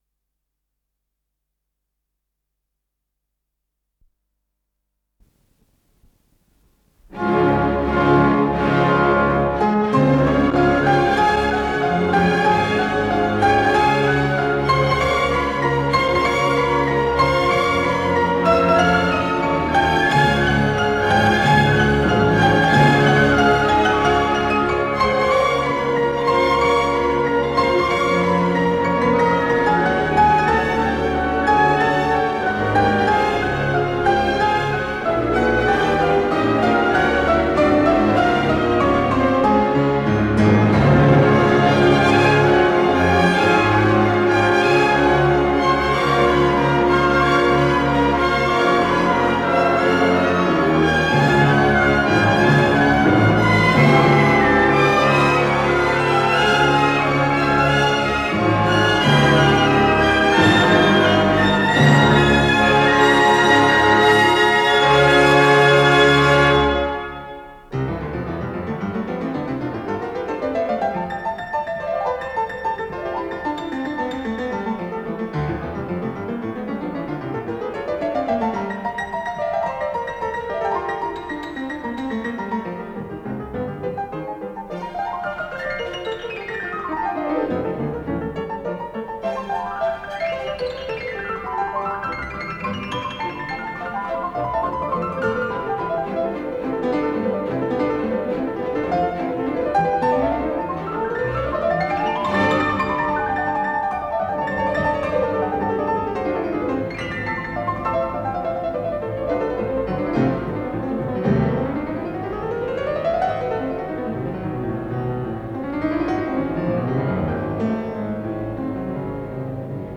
ПКС-018481 — Концерт №1 для фортепиано с оркестром, соч.
Исполнитель: Андрей Гаврилов - фортепиано
№10 Подзаголовок Одночастный, ре бемоль мажор Код ПКС-018481 Фонд Норильская студия телевидения (ГДРЗ) Редакция Музыкальная Общее звучание 00:13:58 Дата записи 1978 Дата переписи 24.12.1981 Дата добавления 06.06.2022 Прослушать